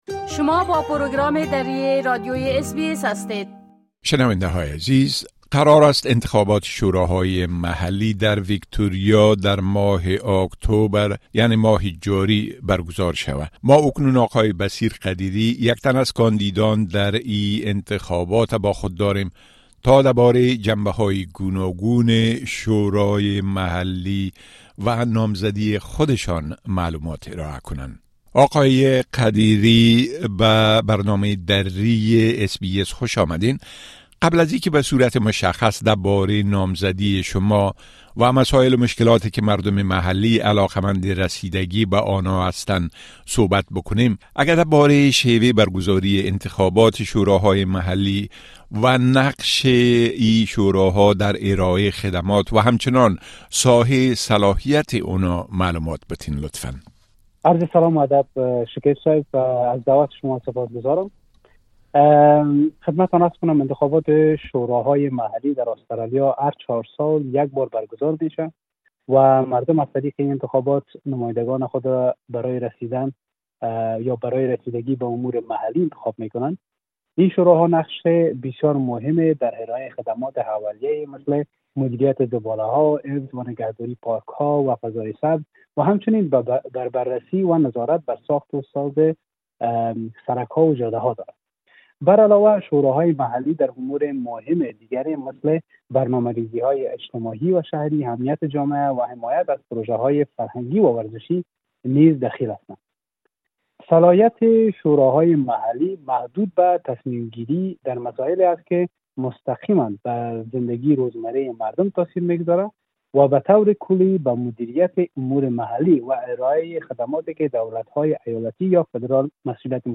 انتخابات شوراهای محلی ایالت ویکتوریا: مصاحبه با یکی از نامزدان